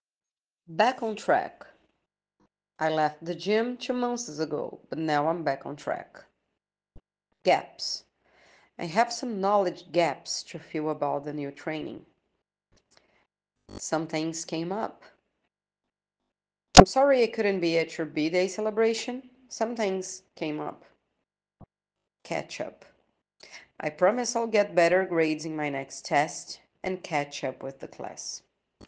ATTENTION TO PRONUNCIATION 🗣